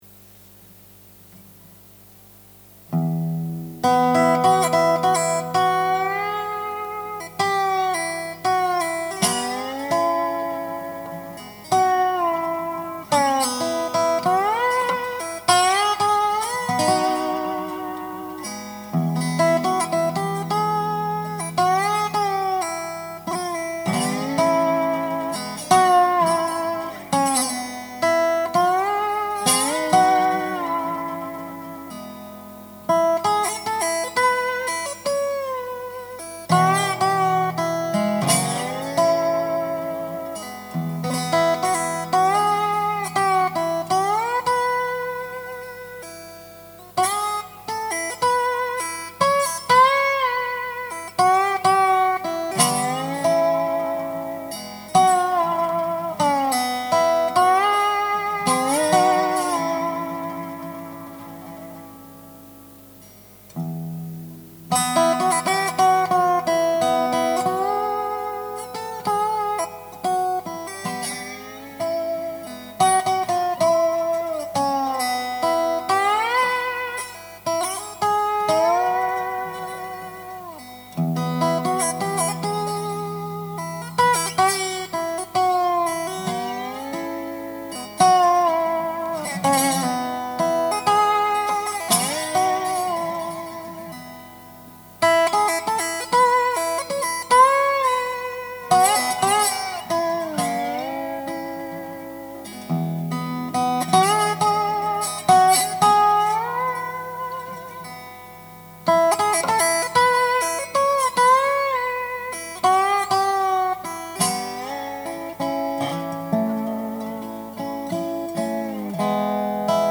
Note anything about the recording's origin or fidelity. :) I should probably spend some more time on the recording – there’s more extraneous noise on this version than I like – but it’s going in the right direction.